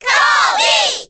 File:Kirby Cheer Korean SSBB.ogg
Category:Crowd cheers (SSBB) You cannot overwrite this file.
Kirby_Cheer_Korean_SSBB.ogg.mp3